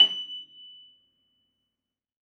53h-pno24-F5.wav